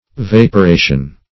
Search Result for " vaporation" : The Collaborative International Dictionary of English v.0.48: Vaporation \Vap`o*ra"tion\, n. [Cf. F. vaporation, L. vaporatio.]
vaporation.mp3